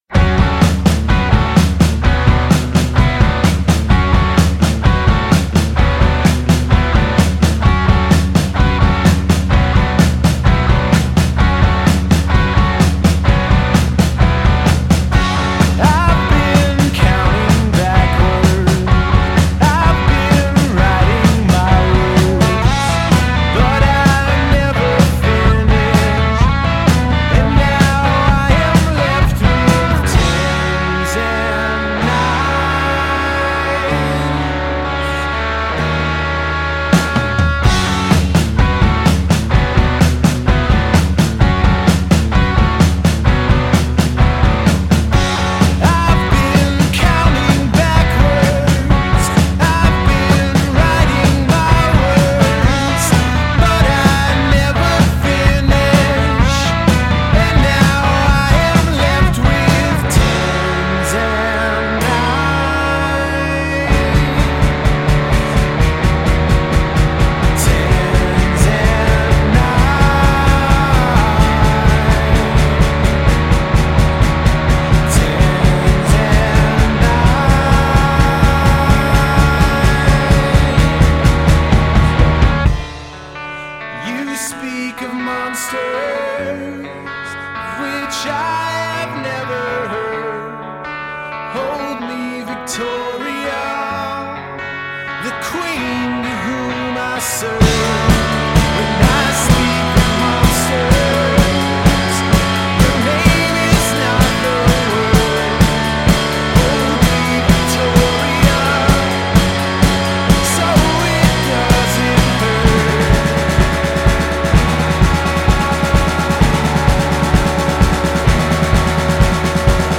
a four piece rock band